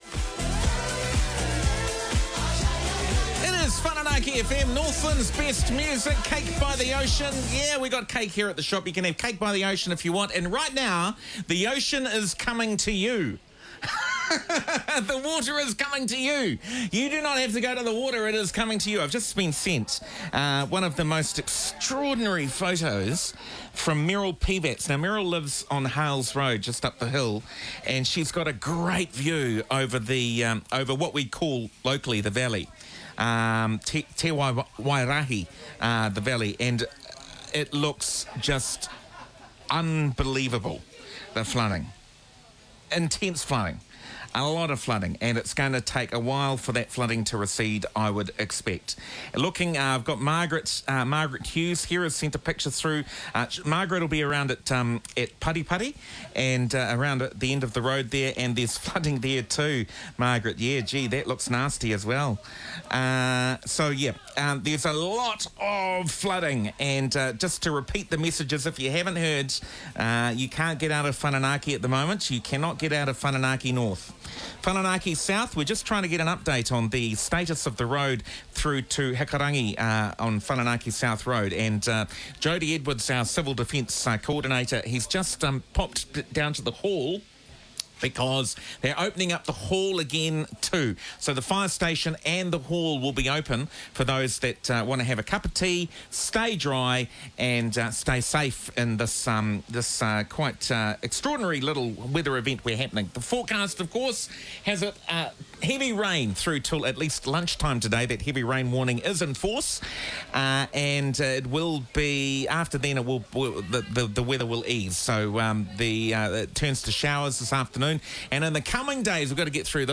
Audio aircheck from this morning.
Background shop noise (customers chatting, general store ambience) gives the broadcast the feel of a live outside broadcast (OB).
Overall, a great example of what “live, local radio” actually sounds like - imperfect, human, community-focused and invaluable during significant local events.
When Whananaki FM goes live to air from a general store, its studio was not fully soundproofed.